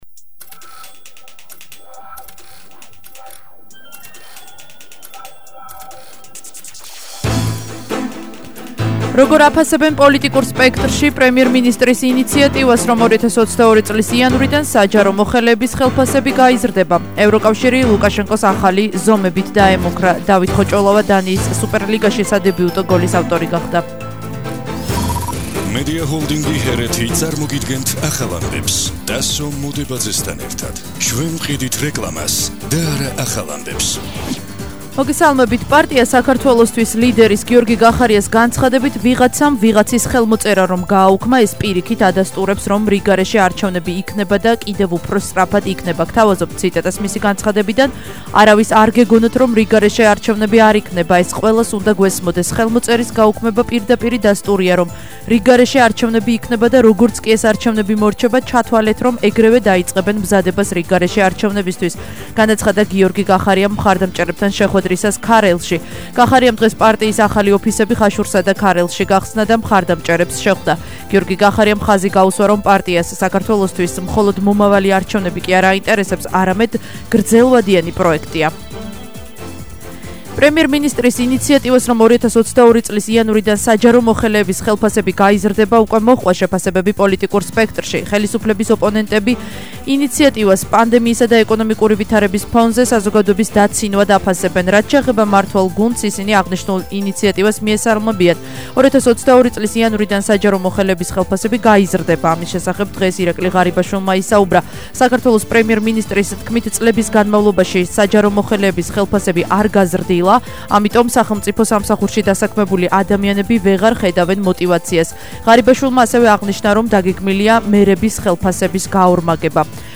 ახალი ამბები 16:00 საათზე –09/08/21 - HeretiFM